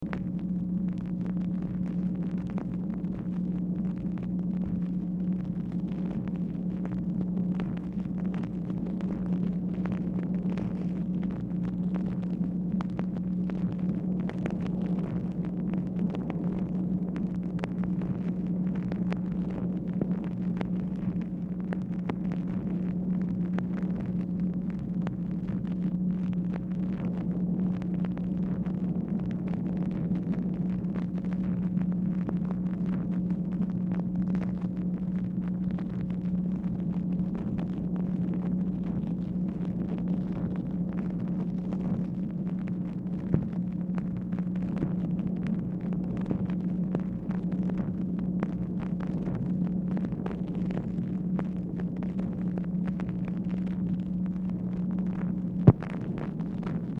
Telephone conversation # 13517, sound recording, MACHINE NOISE, 10/7/1968, time unknown | Discover LBJ
Dictation belt